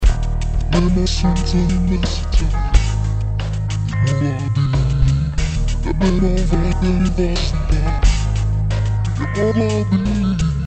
Pitch shifter